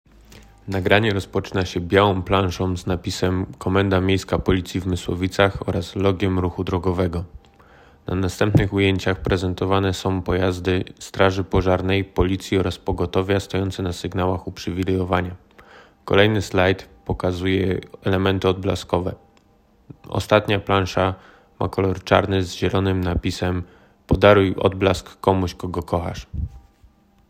Opis nagrania: audiodeskrypcja do filmu